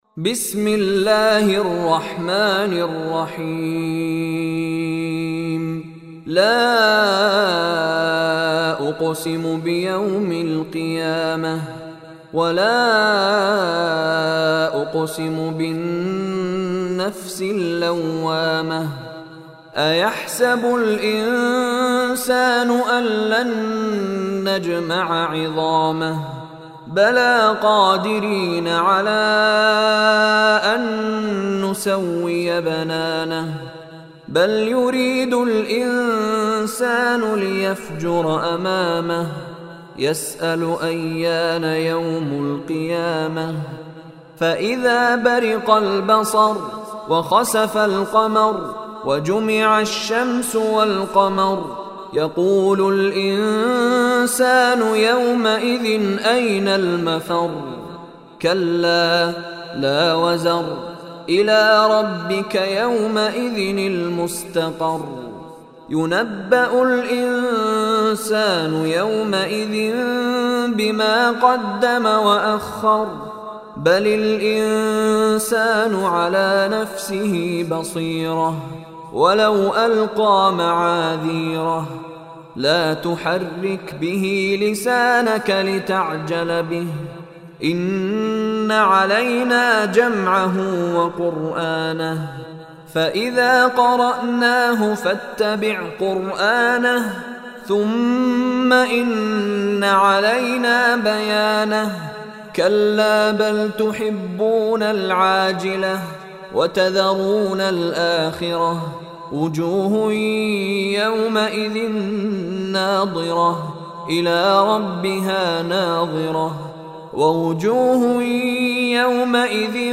Surah Qiyamah Online Recitation by Mishary Rashid
Surah Al-Qiyamah, listen online mp3 or download audio recitation in arabic in beautiful voice of Shiekh Mishary Rashid Alafasy.